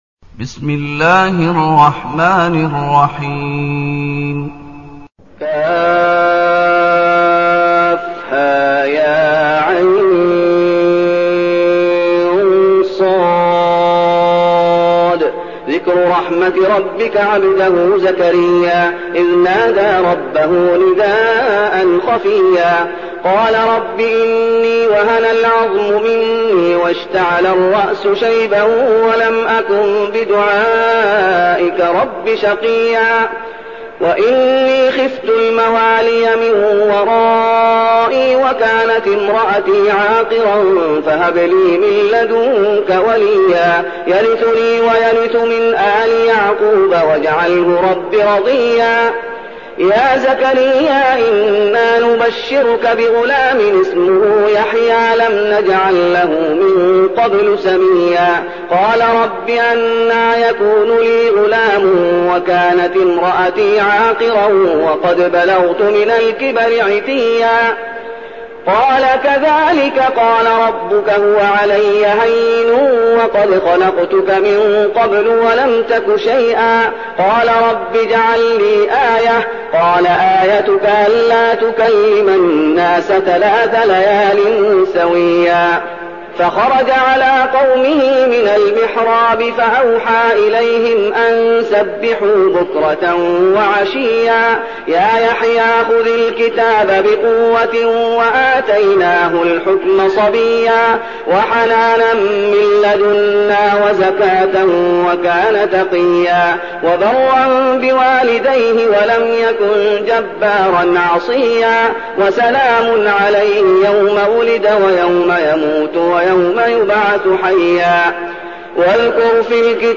تلاوة سورة مريم بصوت الشيخ محمد أيوب
تاريخ النشر ١ محرم ١٤١٠ المكان: المسجد النبوي الشيخ: فضيلة الشيخ محمد أيوب فضيلة الشيخ محمد أيوب سورة مريم